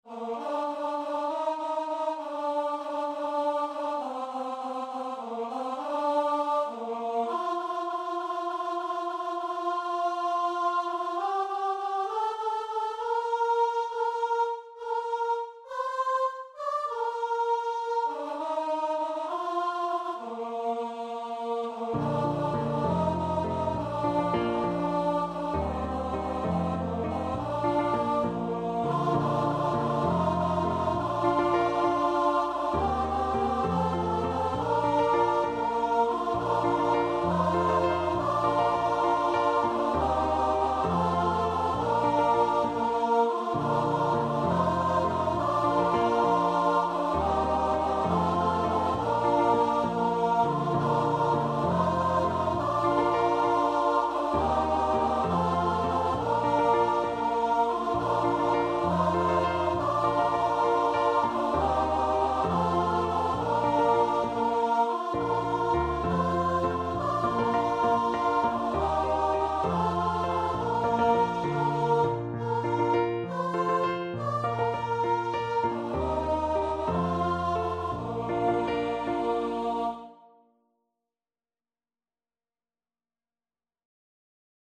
Free Sheet music for Choir (SATB)
6/8 (View more 6/8 Music)
Choir  (View more Easy Choir Music)
Traditional (View more Traditional Choir Music)